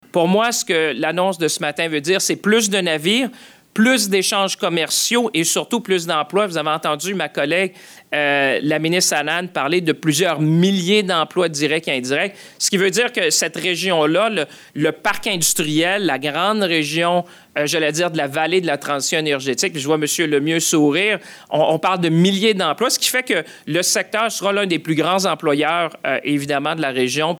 L’investissement qui a été annoncé en conférence de presse lundi avant-midi est donc de 327,6 M$.
Le ministre fédéral  de l’Innovation, des Sciences et de l’Industrie, François-Philippe Champagne, a souligné que ce projet était une pièce nécessaire dans un grand ensemble industriel.